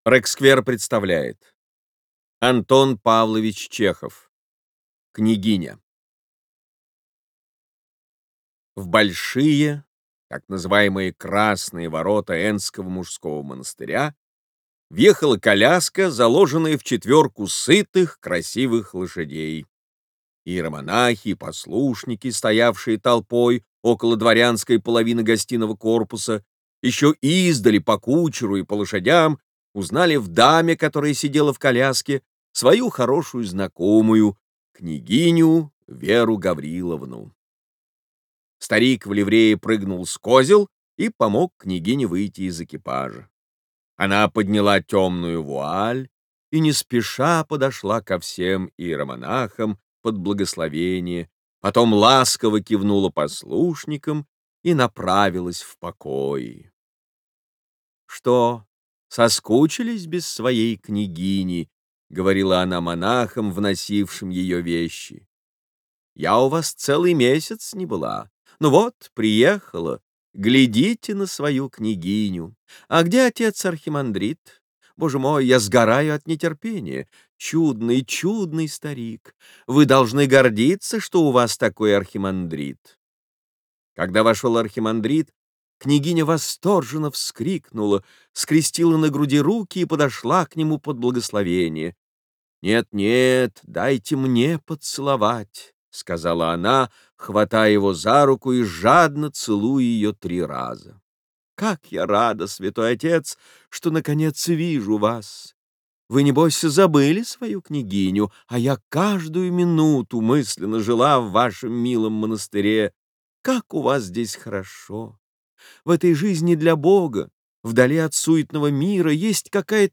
Классическую литературу в озвучке «Рексквер» легко слушать и понимать благодаря профессиональной актерской игре и качественному звуку.
Chekhov_Kniaginia_Recsquare Audiobooks.mp3